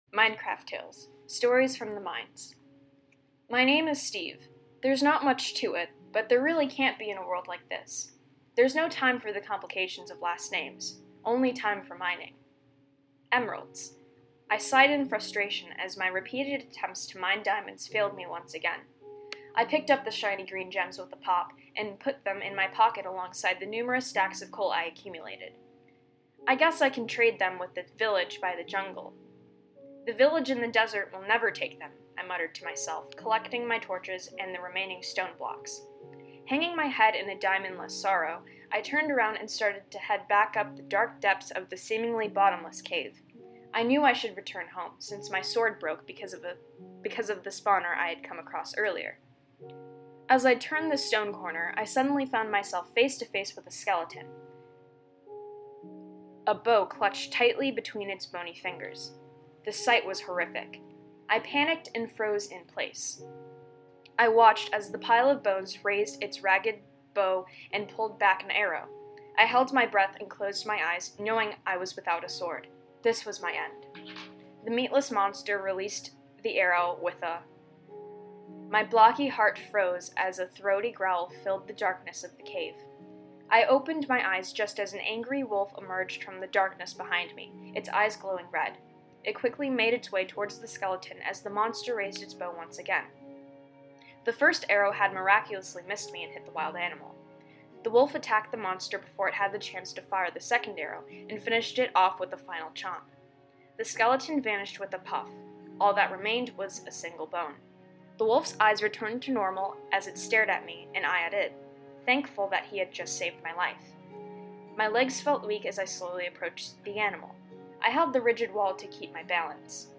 Narrated